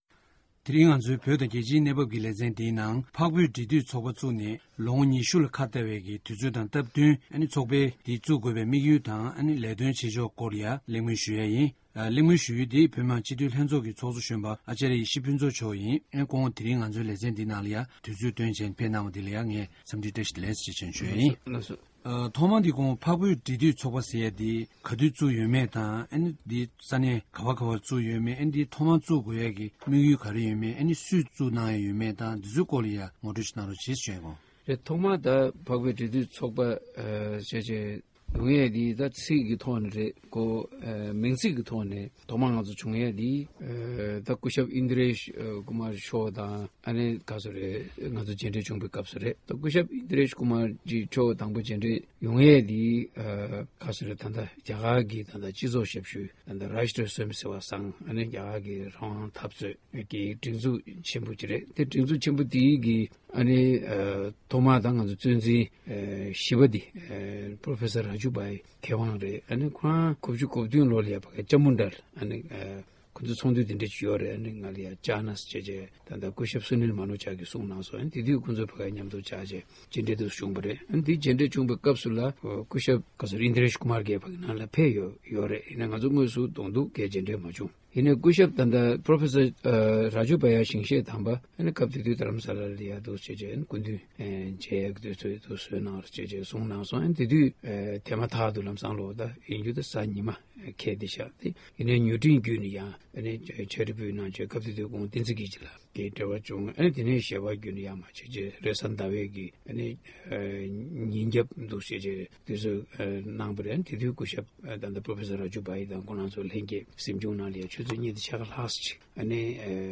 བཙན་བྱོལ་བོད་མི་མང་སྤྱི་འཐུས་ཚོགས་གཞོན་ཨཱ་ཙརྱ་ཡེ་ཤེས་ཕུན་ཚོགས་ལགས་སུ་གནས་འདྲི་ཞུས་པར་གསན་རོགས༎